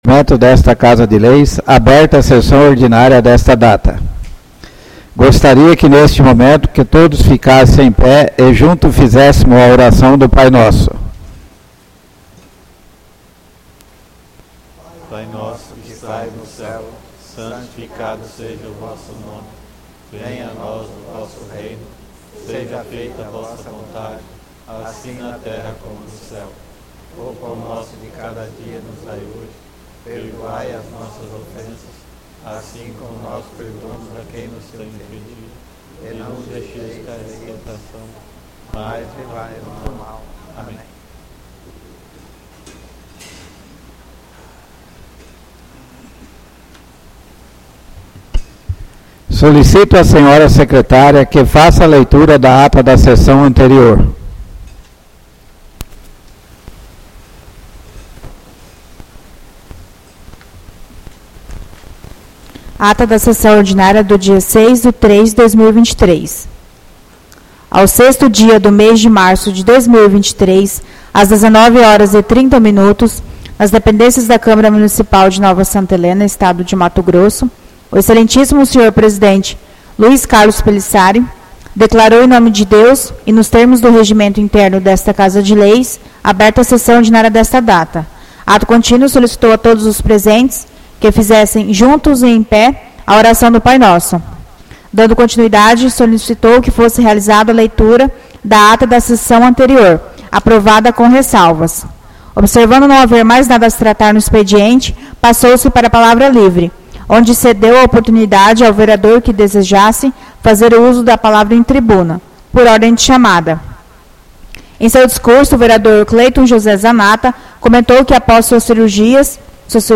Sessões Plenárias